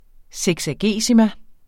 seksagesima substantiv, fælleskøn Udtale [ sεgsaˈgeˀsima ]